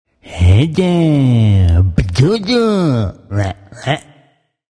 (dieses r ist etwa wie im engl. "Rabbit" auszusprechen).